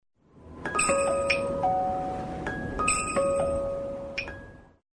Index of /phonetones/unzipped/LG/KU5500/Message tones